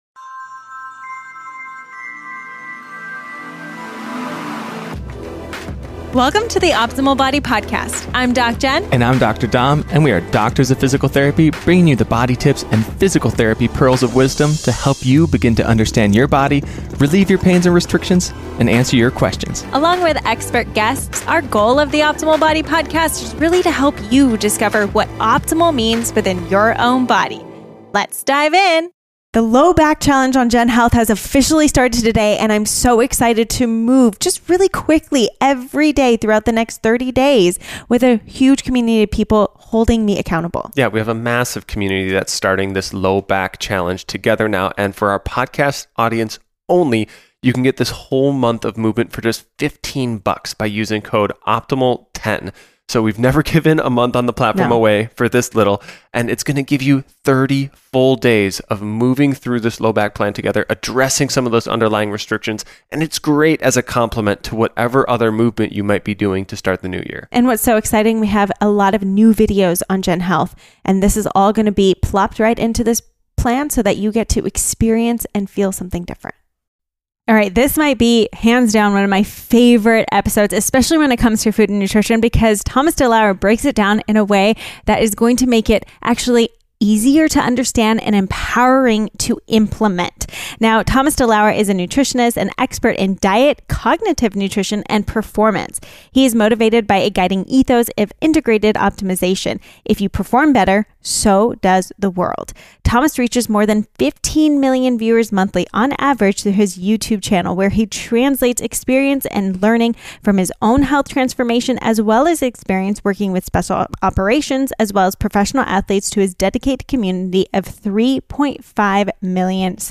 What You Will Learn in This Interview with  Thomas DeLauer : 3:06 - What set Thomas on the path of healthcare and nutrition?